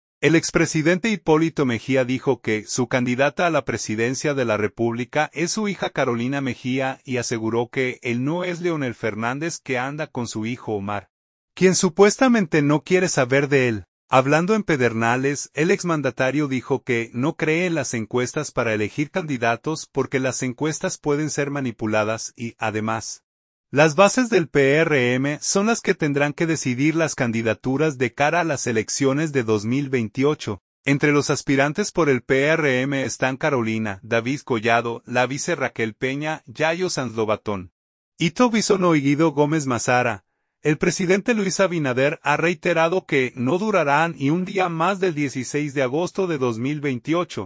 Hablando en Pedernales, el exmandatario dijo que no cree en las encuestas para elegir candidatos, porque las encuestas pueden ser manipuladas y, además, las bases del PRM son las que tendrán que decidir las candidaturas de cara a las elecciones de 2028.